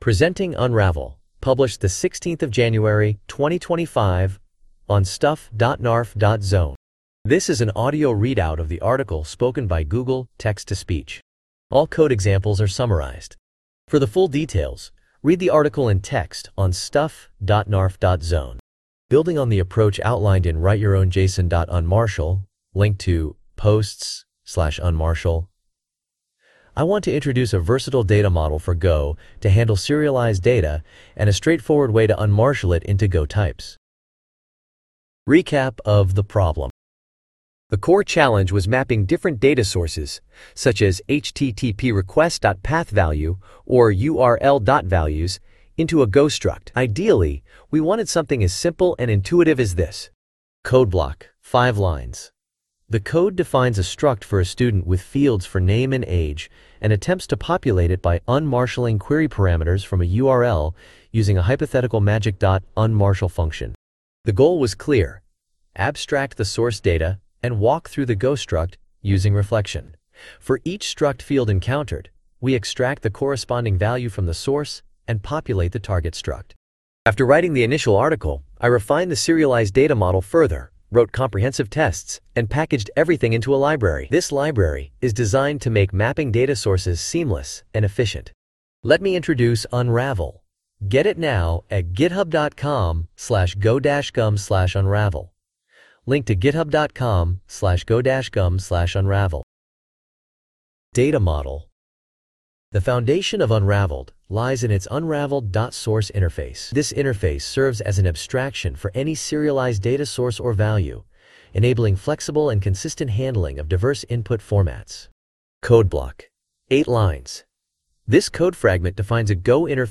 unravel-tts.mp3